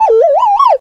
Media:sprout_kill_vo_05.ogg Weird Sound.
奇怪的声音